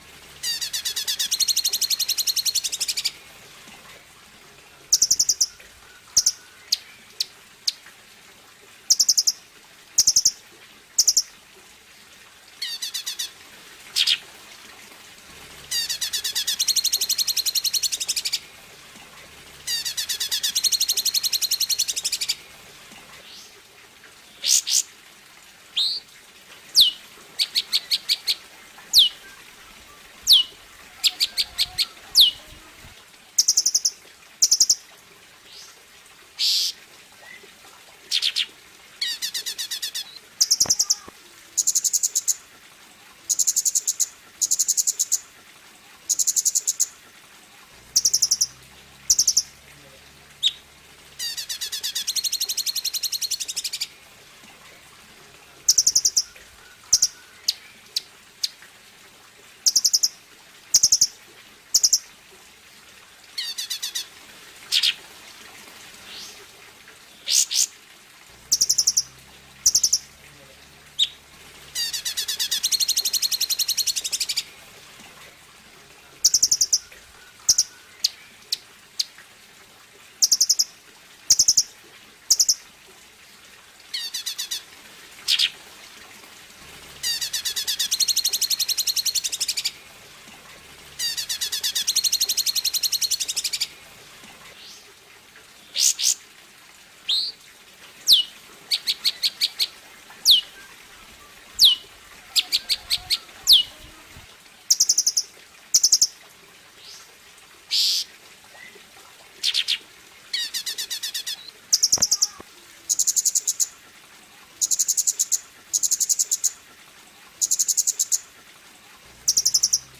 Suara Burung Cucak Cungkok
Kategori: Suara burung
Keterangan: Download suara burung Cucak Cungkok ngerol panjang dengan kualitas gacor istimewa. Suaranya jernih, tembakan keras, dan cocok untuk masteran burung.
suara-burung-cucak-cungkok-id-www_tiengdong_com.mp3